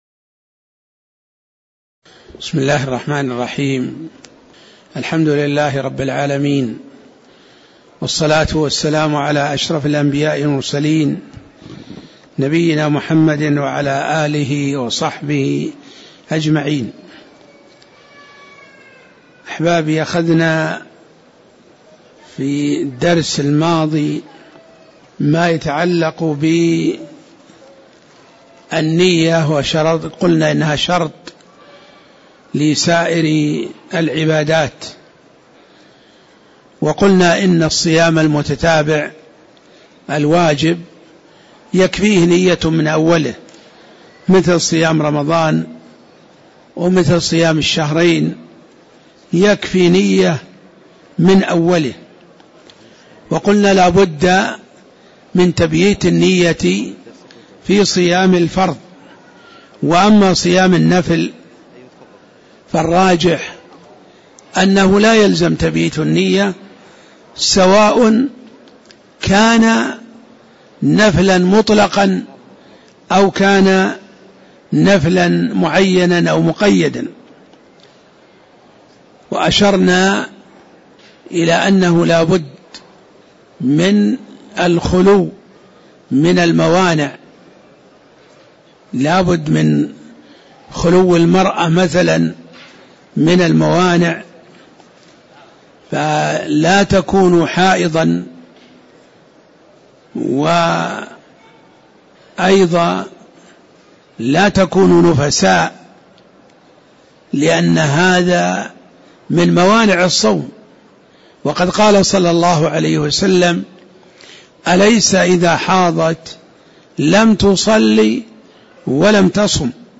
تاريخ النشر ٢١ شعبان ١٤٣٧ هـ المكان: المسجد النبوي الشيخ